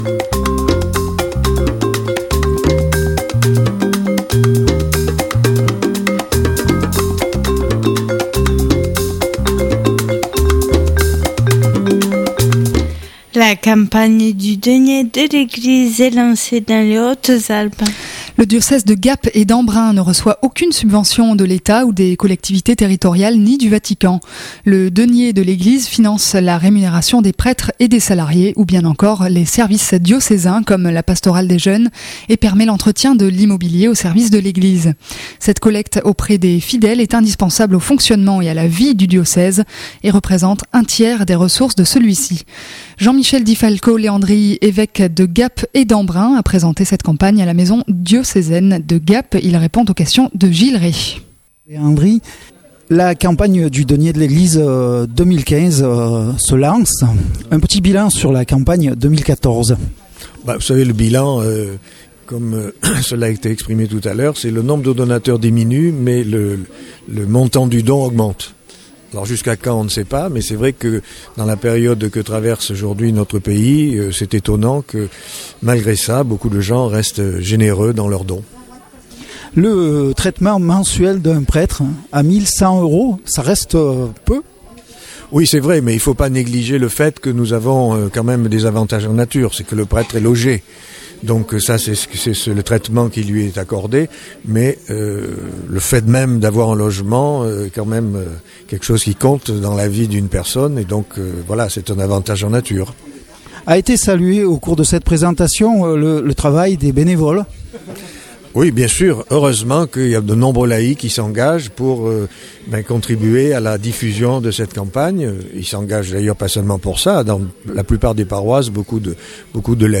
Cette collecte auprès des fidèles est indispensable au fonctionnement et à la vie du diocèse et représente 1/3 des ressources de celui-ci. Jean-Michel Di Falco Léandri, évêque de Gap et d'Embrun, a présenté cette campagne à la maison diocésaine de Gap.